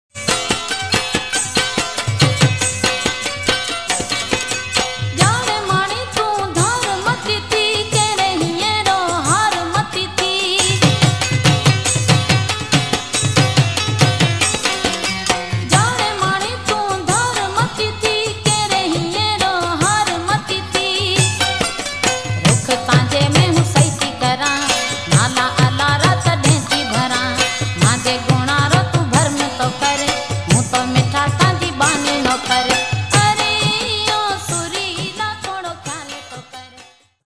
Marwari Song